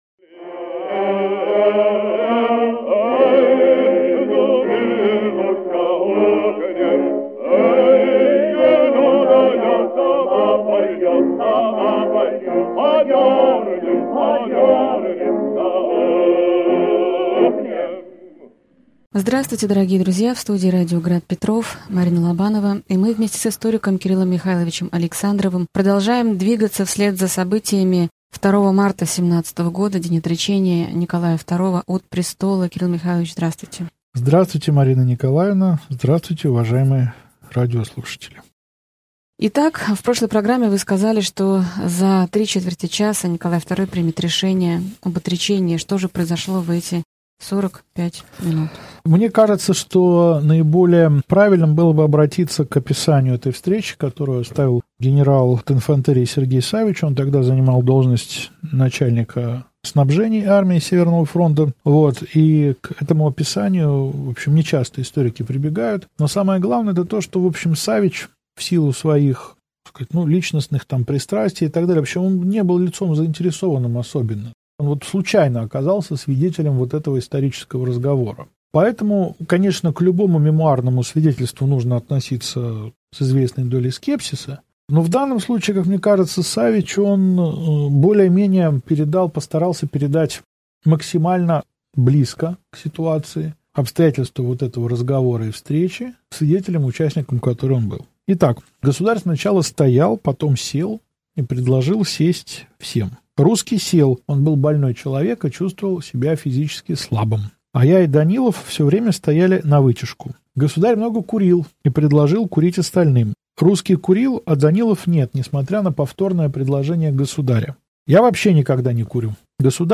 Аудиокнига Февральская революция и отречение Николая II.